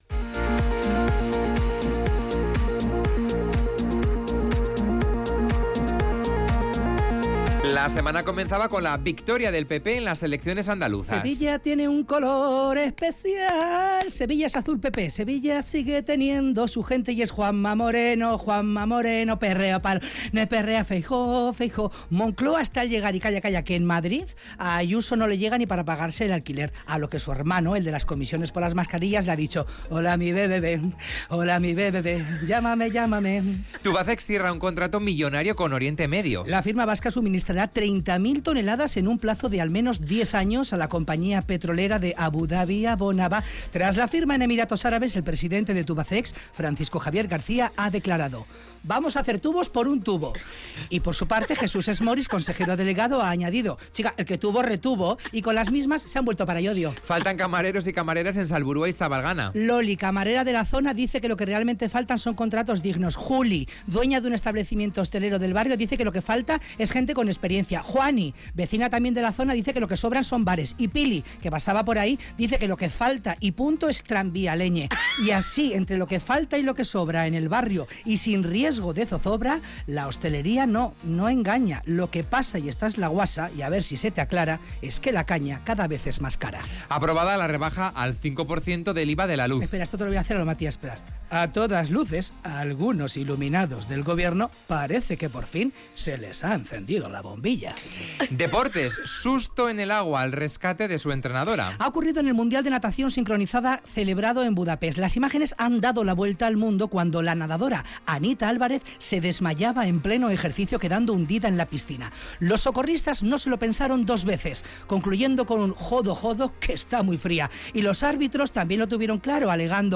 Las noticias con color, con audio, con una entonación única todo ello elaborado por nuestro compañero.